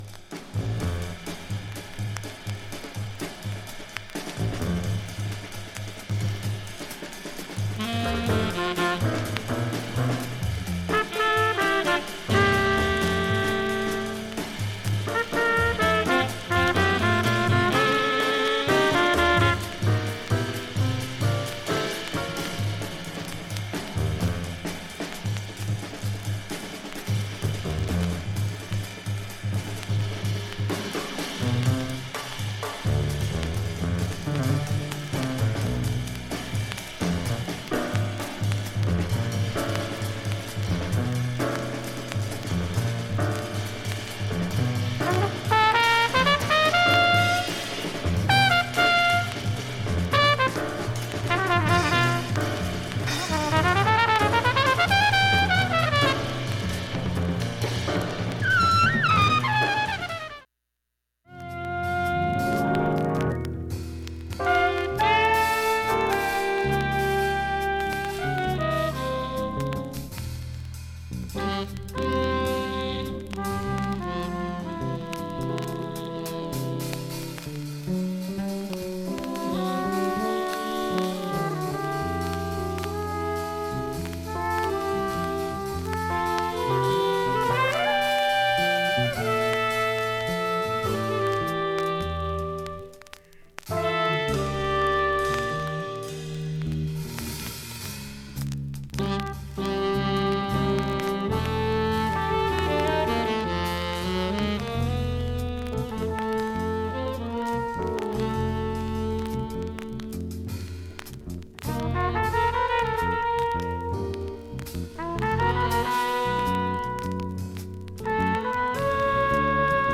下記静かな部などでチリプツ出ます。
1,A-1始め60秒間軽いプツ、プツプツ出ます。
2,(1m01s〜)A-2序盤に軽いチリプツ出ます。
2 Eye 360 SOUND STEREO